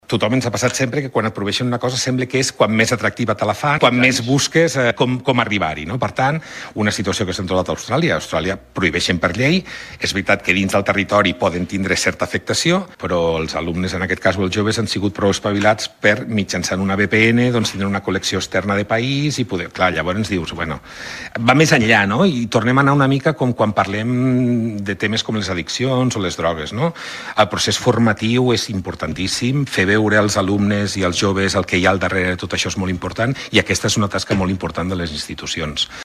El secretari d’Estat d’Educació i Universitats, Josep Anton Bardina, considera que prohibir l’ús de les xarxes socials als menors és una mesura “complexa i difícil d’aplicar”. Ho ha explicat en una entrevista a l’Avui serà un bon dia, a RTVA, on ha assenyalat que l’experiència d’altres països, com Austràlia, demostra que els joves acaben trobant mecanismes per esquivar les restriccions.